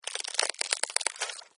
Здесь собраны забавные и неожиданные аудиоэффекты: от прыжков до едва уловимого шуршания.
Звук блохи в поисках места для укуса